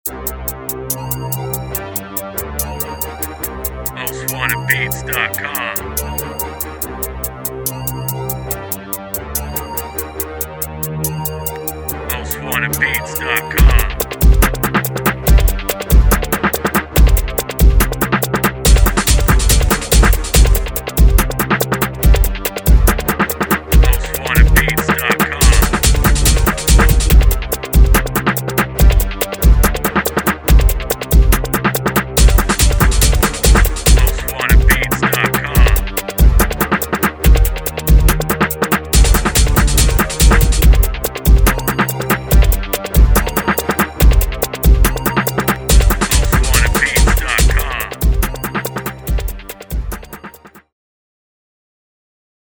HIP HOP INSTRUMENTAL